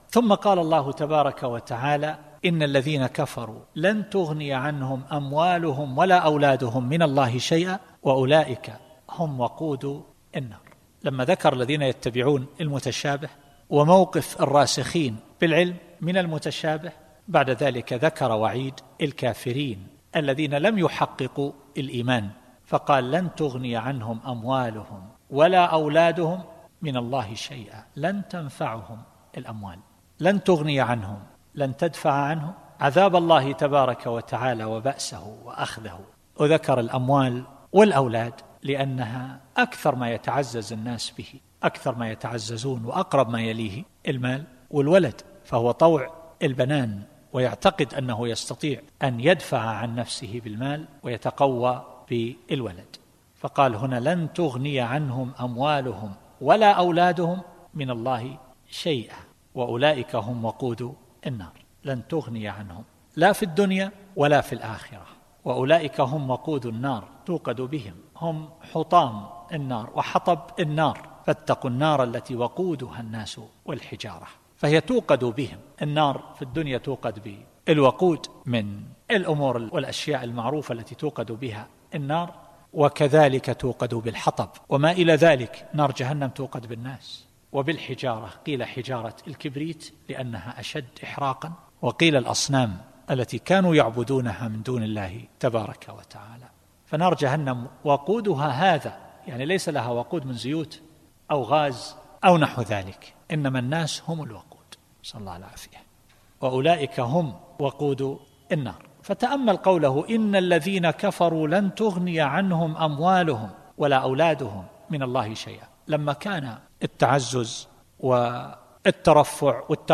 التفسير الصوتي [آل عمران / 10]